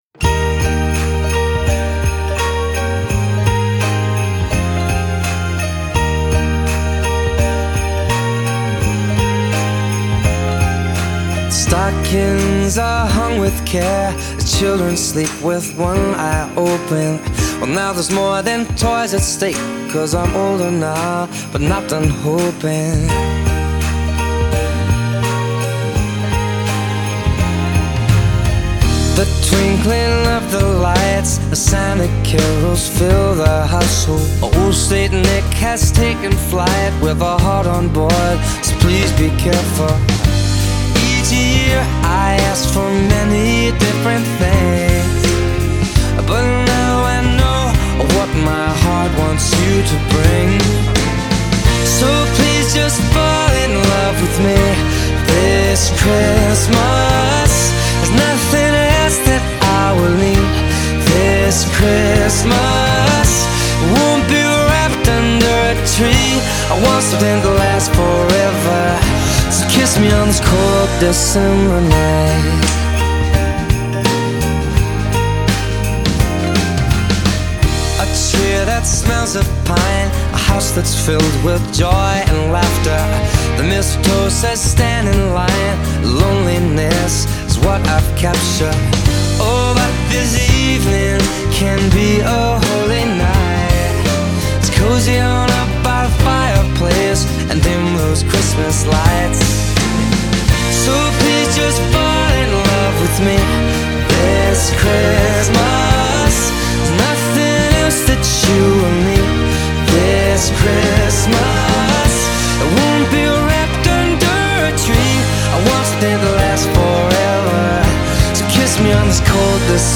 BPM84-84
Audio QualityPerfect (High Quality)
Christmas song for StepMania, ITGmania, Project Outfox
Full Length Song (not arcade length cut)